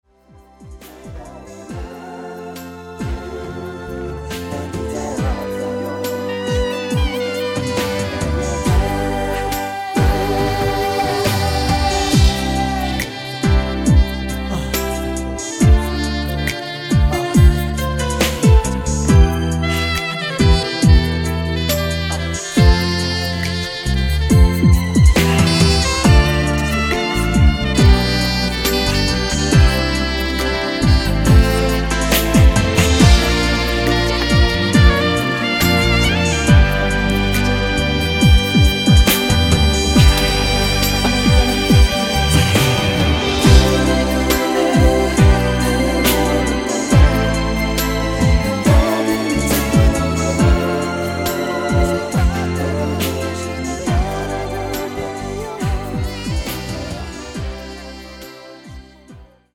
음정 원키 5:00
장르 가요 구분 Voice MR
보이스 MR은 가이드 보컬이 포함되어 있어 유용합니다.